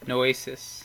Ääntäminen
Vaihtoehtoiset kirjoitusmuodot noêsis noësis noēsis Ääntäminen US Tuntematon aksentti: IPA : /nəʊˈiːsɪs/ IPA : /noʊˈisᵻs/ Haettu sana löytyi näillä lähdekielillä: englanti Käännöksiä ei löytynyt valitulle kohdekielelle.